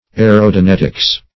Search Result for " aerodonetics" : The Collaborative International Dictionary of English v.0.48: Aerodonetics \A`["e]*ro*do*net"ics\, n. [A["e]ro- + Gr.